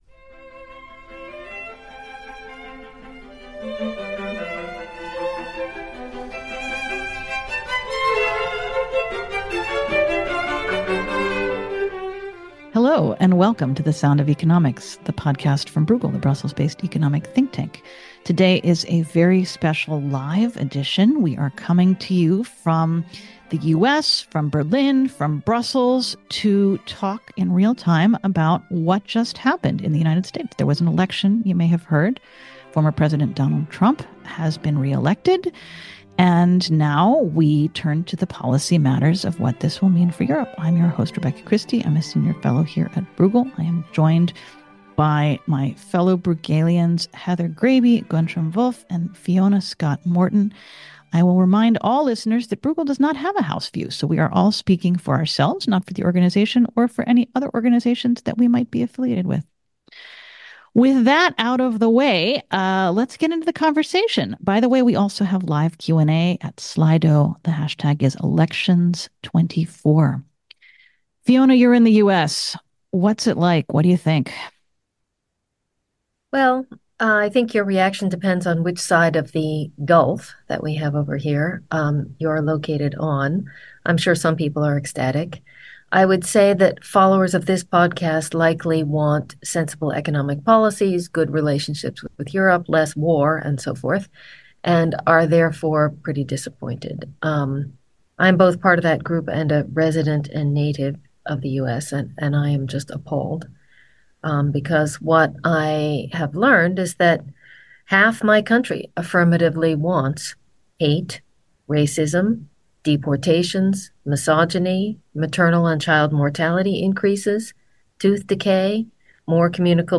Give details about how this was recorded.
In this special live episode of The Sound of Economics podcast,